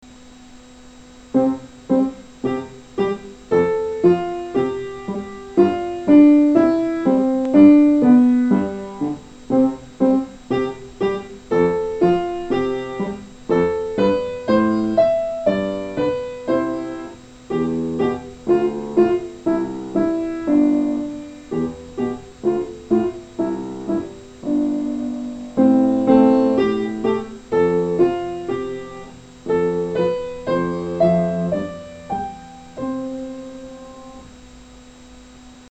Quarter note rhythm
negra_oques.mp3